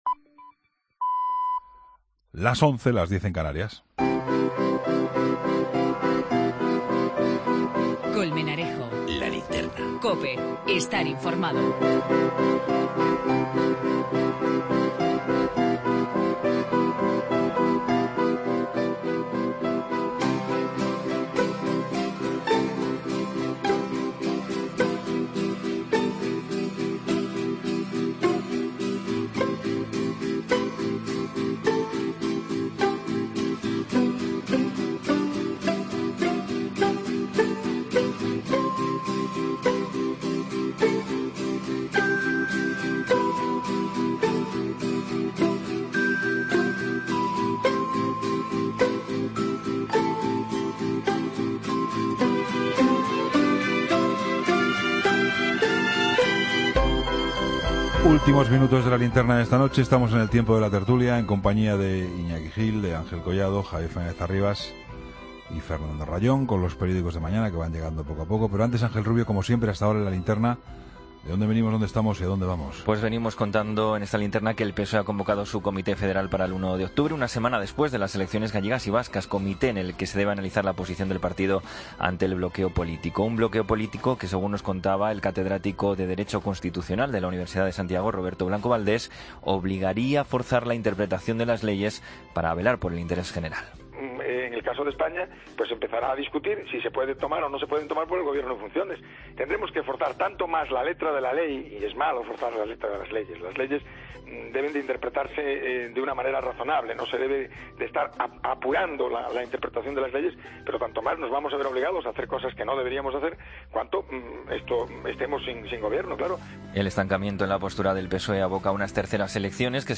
Tertulia II, miércoles de septiembre de 2016